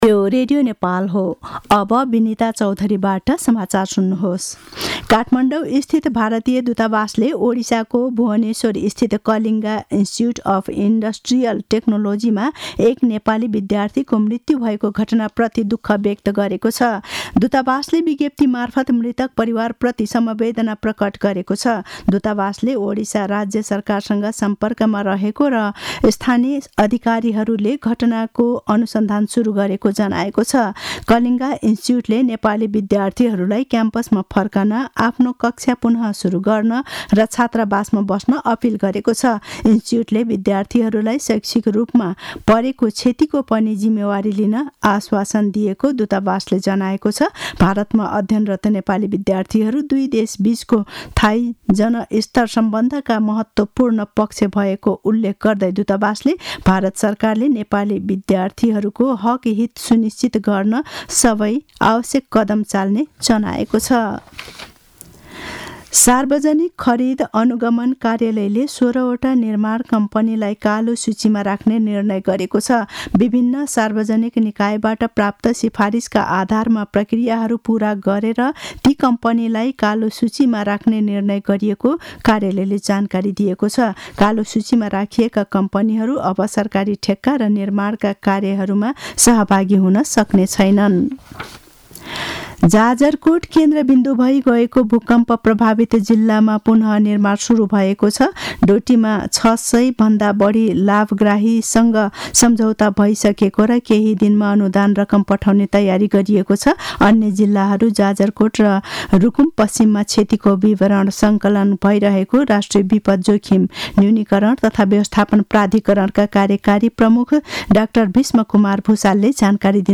दिउँसो १ बजेको नेपाली समाचार : ७ फागुन , २०८१